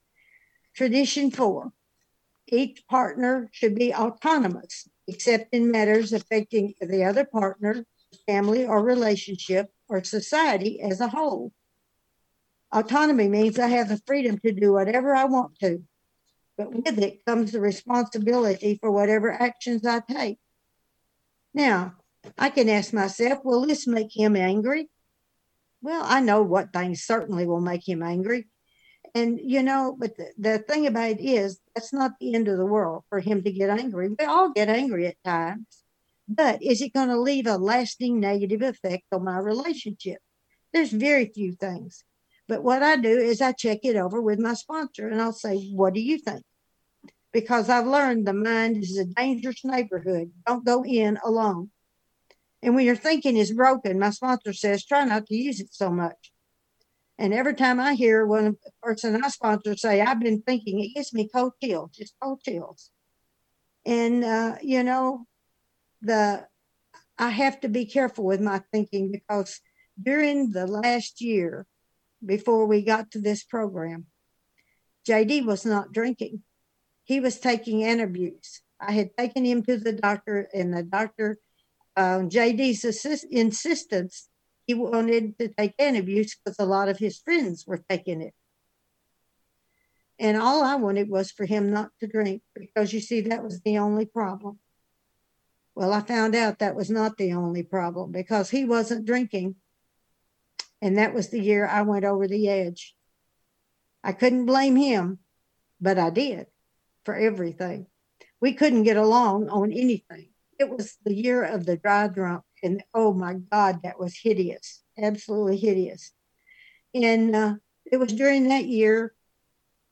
AWB Workshop - Traditions in Relationships